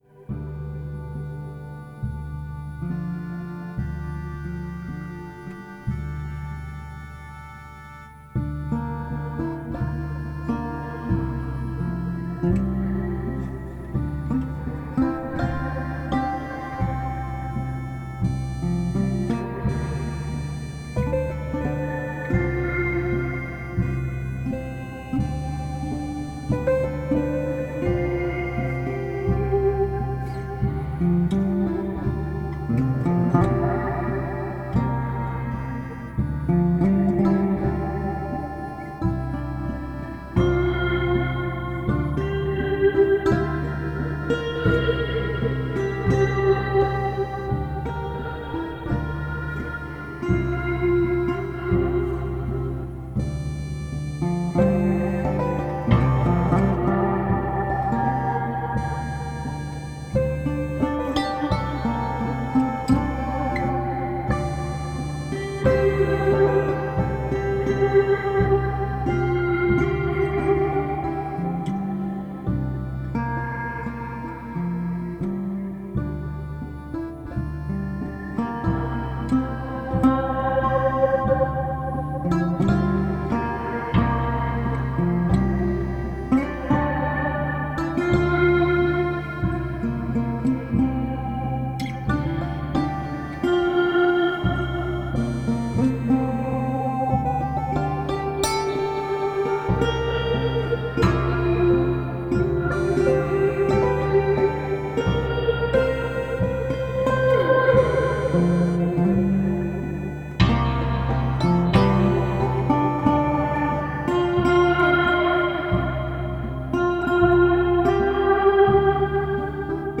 Folk, Country & Ambient /